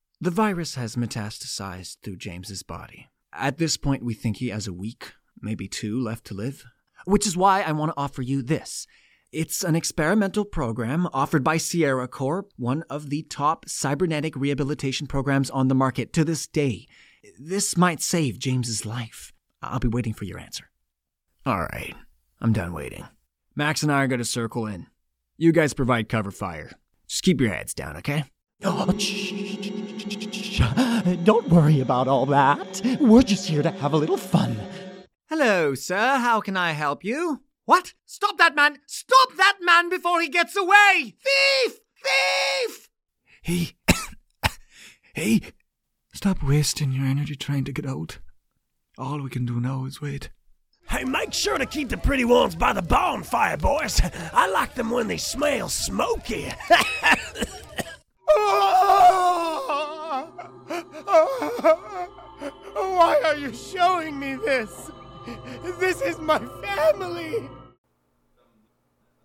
Jeux vidéos - ANG